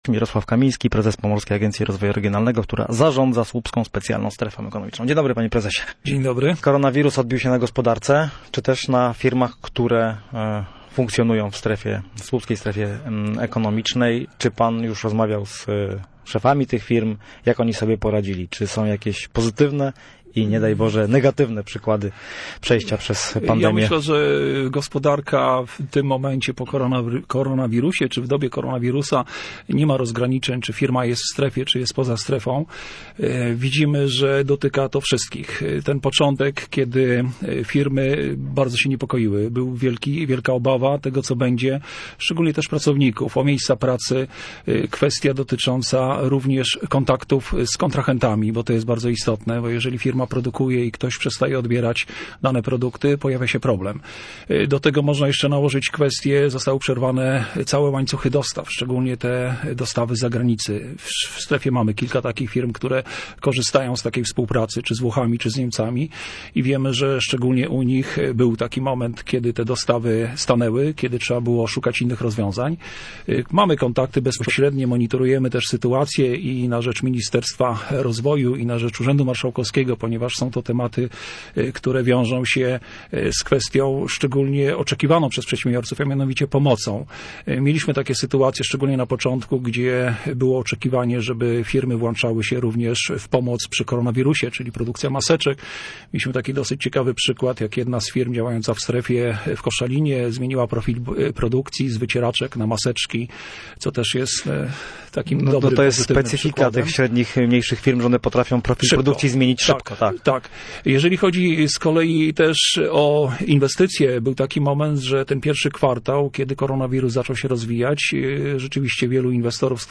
Całej rozmowy